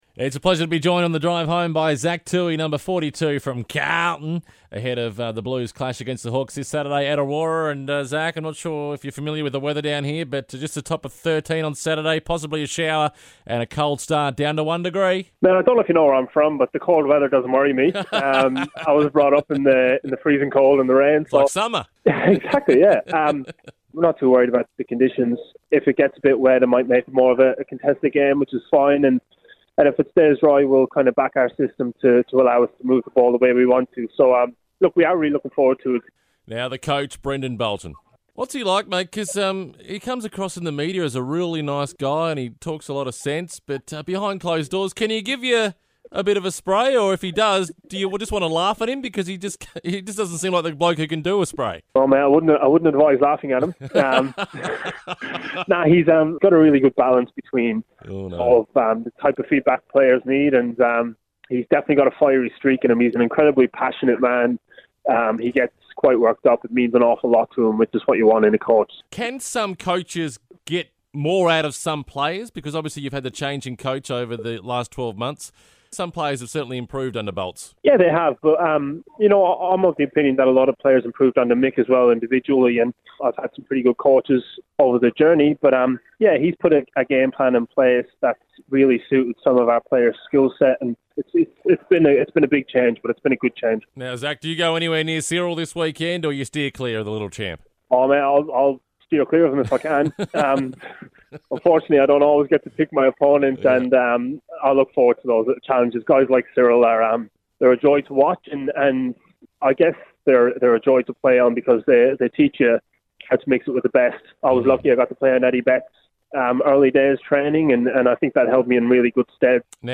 Carlton defender Zach Tuohy speaks to LAFM Launceston in the lead-up to the Blues' clash against Hawthorn at Aurora Stadium.